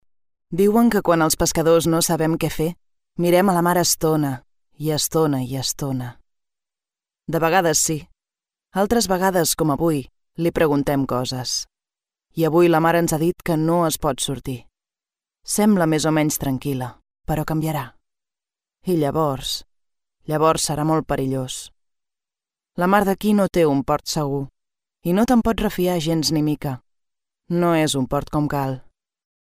Warm, Zacht, Natuurlijk, Vriendelijk, Jong
Explainer